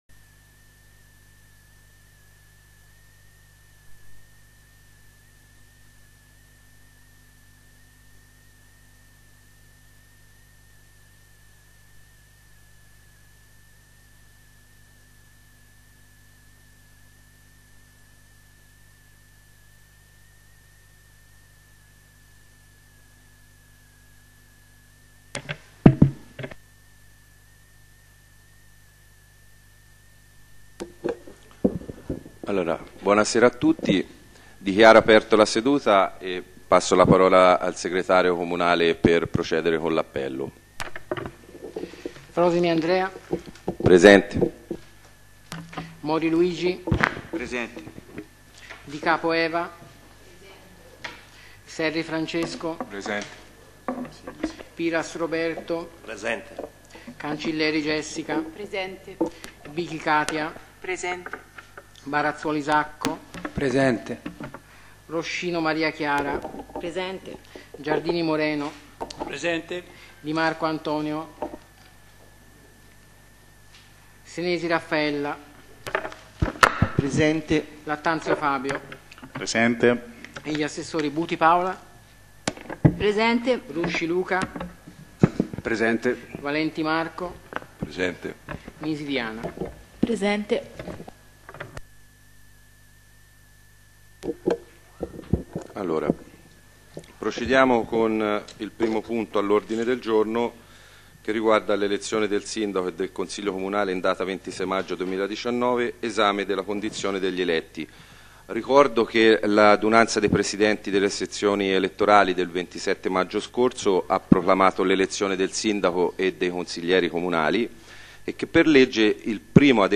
Consiglio Comunale 13 giugno 2019 - Comune di Monteriggioni